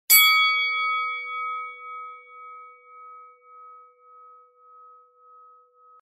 Звуки китайского колокольчика
На этой странице собраны звуки китайских колокольчиков — нежные, мелодичные и наполненные восточным колоритом.
Один динь для внимания